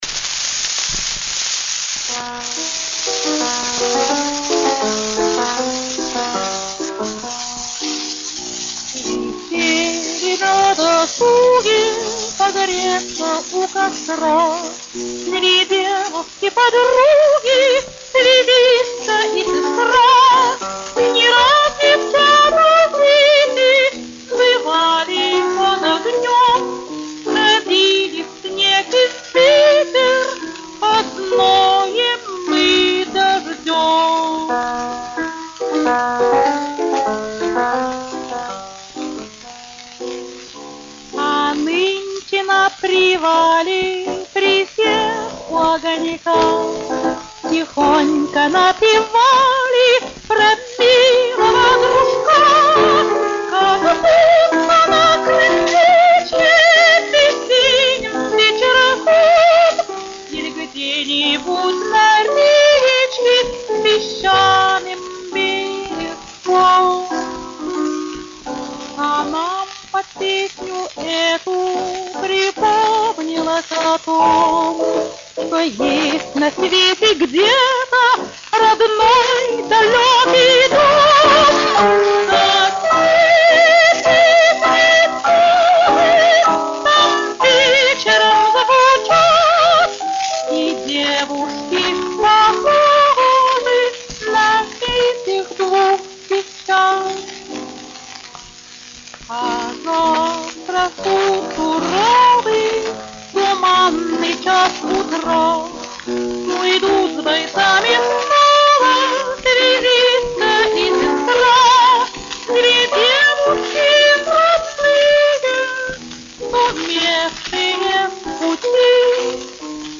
Описание: Очень редкий вариант исполнения.
ф-но